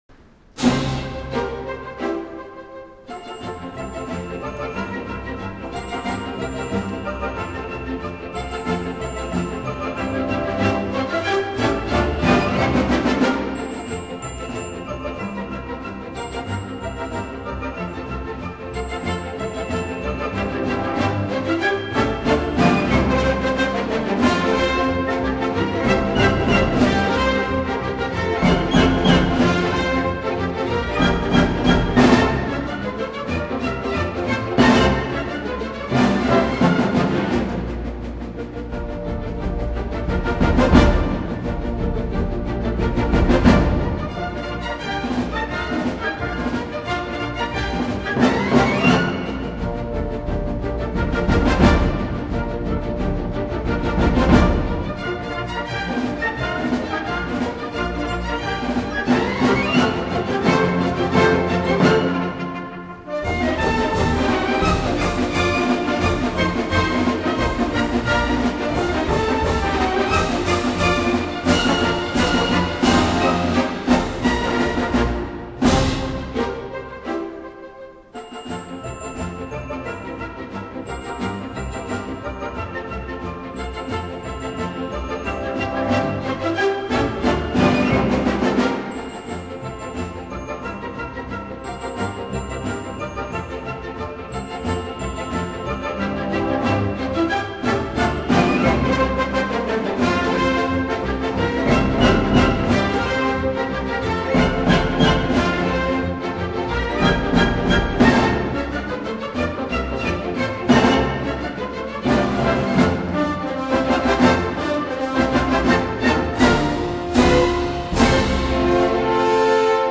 他的指揮下的音樂，音色華麗柔美，既能展現出銅管樂器的嘹亮高亢，又能展現出絃樂器的優雅醇厚。
Polka schnell